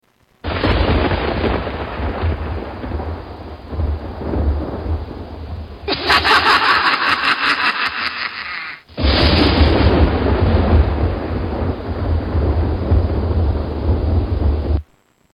Evil laugh with thunder
Tags: halloween animal calls scary animals sounds